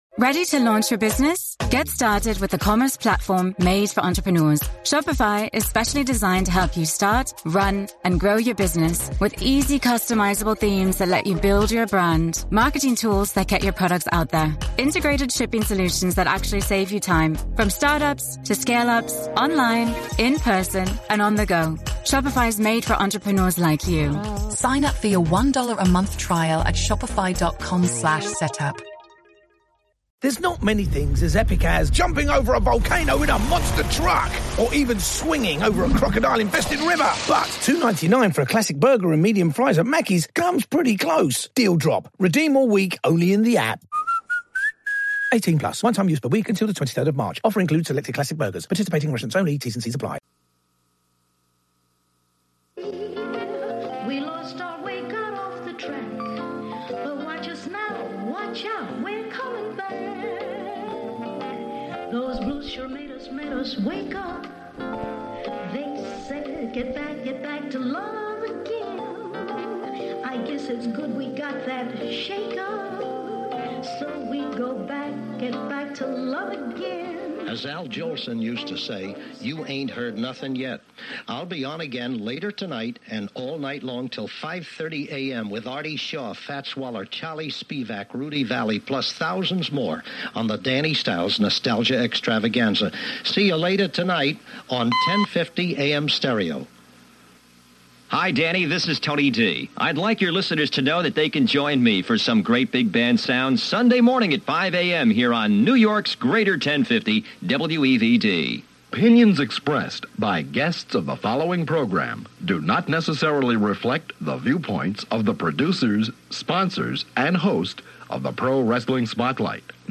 On this episode we share the March 6th, 1993 broadcast of Pro Wrestling Spotlight, which aired on 1050 AM WEVD in New York City, NY. Mexican Sensation KONNAN makes his Pro Wrestling Spotlight debut - appearing in studio.
Honky Tonk Man calls in and causes a disruption - only to get Funk riled up and a shoot barrage from the Funker ensues! Callers weigh in, and more!